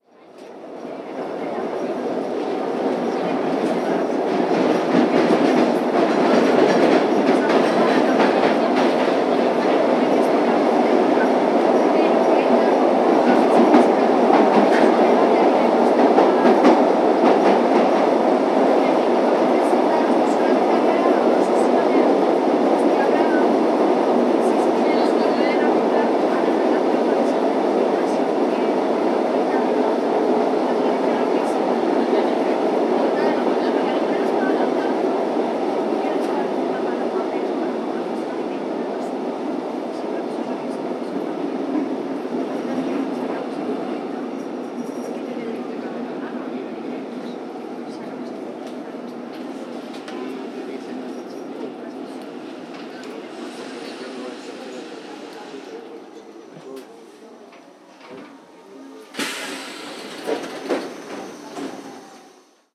Interior de un vagón de Metro
Sonidos: Transportes
Sonidos: Ciudad